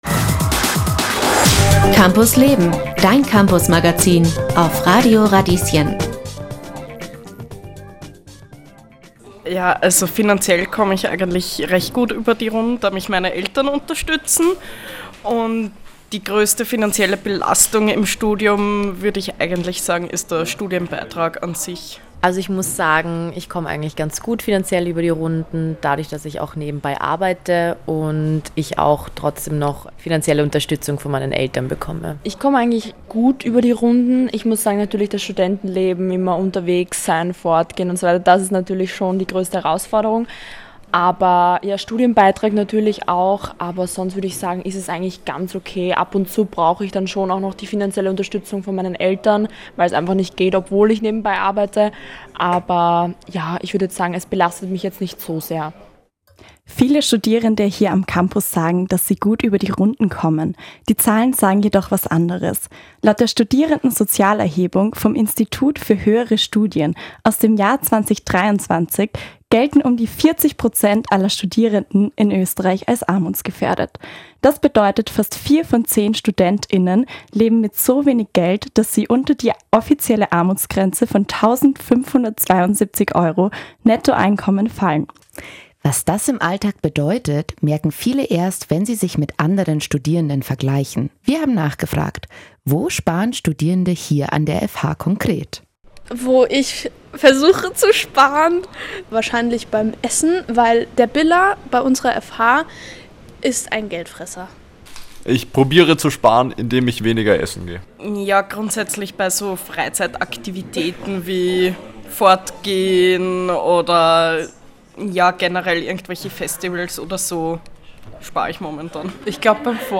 Dieser Podcast ist ein Ausschnitt aus der Campus Leben-Livesendung vom 18. Juni auf Radio Radieschen.